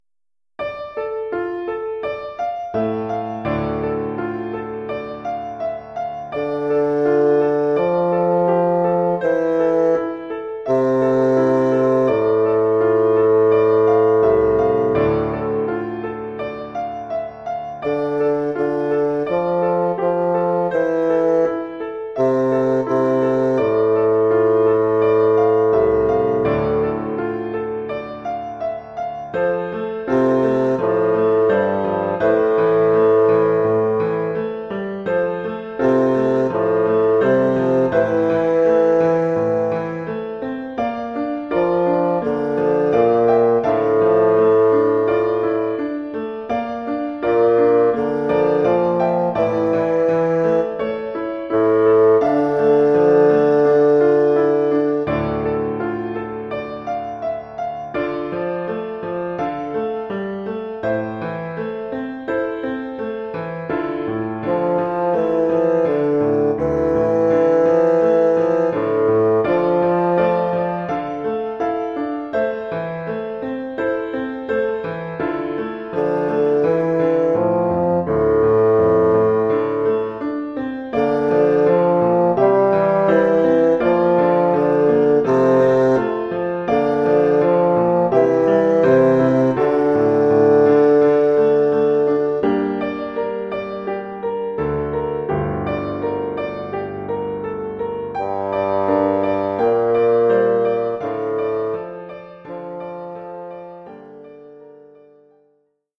Formule instrumentale : Basson et piano
Oeuvre pour basson et piano.